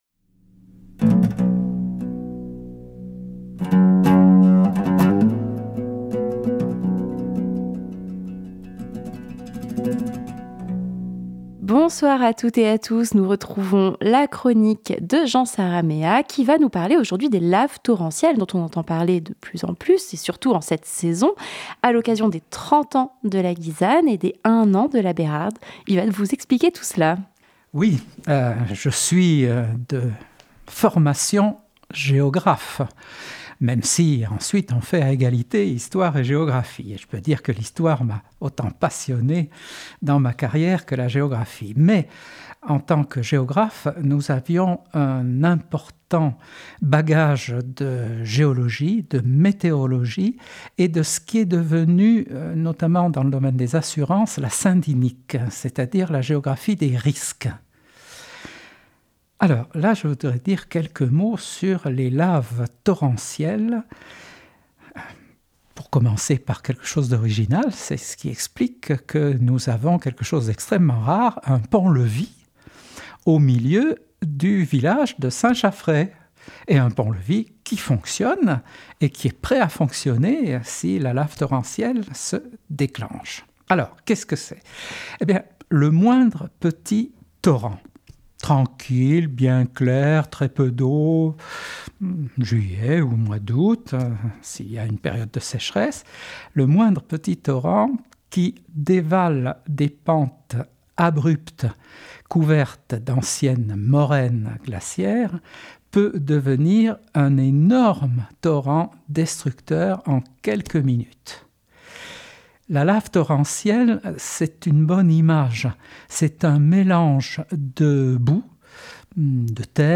historien-géographe retraité vous dit tout sur les laves torrentielles dont on entend de plus en plus parler avec le changement climatique.